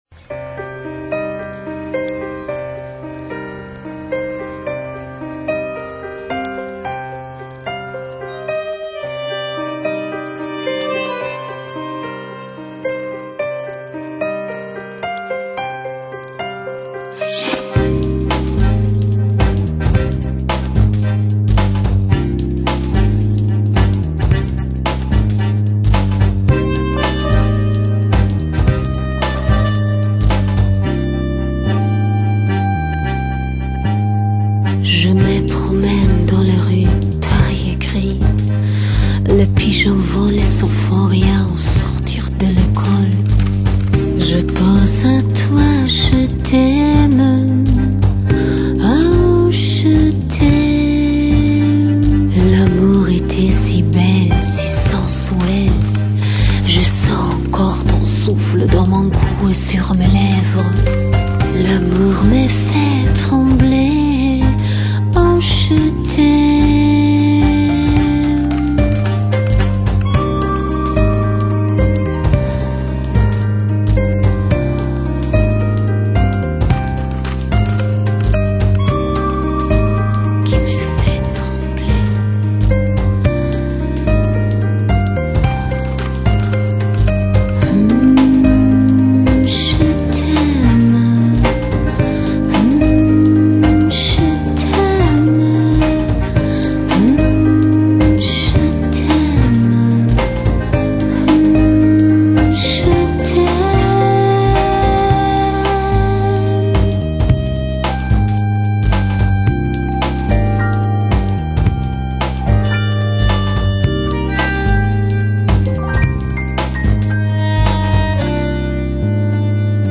Bossa Nova可说是巴西人最伟大的音乐发明，以细碎的节拍与清淡的器乐还有慵懒的歌声崛起于60年代。
在他们专擅的电子缓拍音乐中，弥漫着Bossa Nova舒适优美的氛围